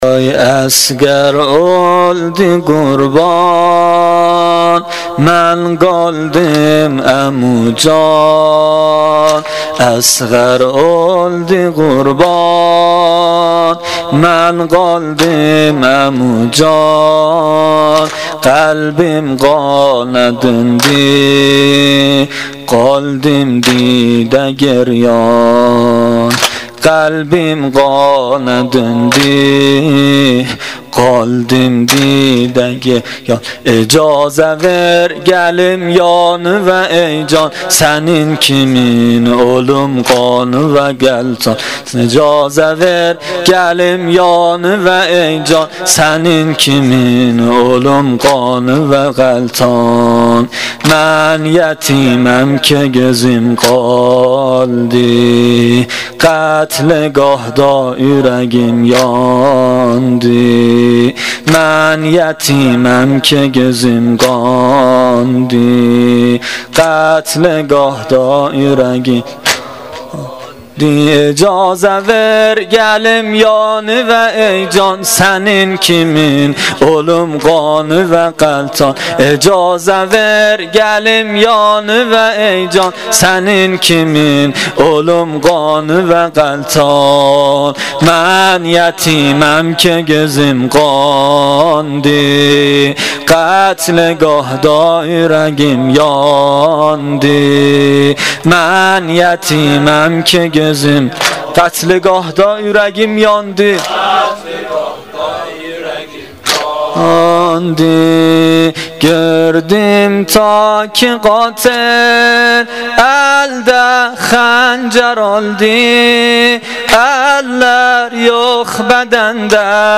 واحد ترکی شب پنجم محرم الحرام 1396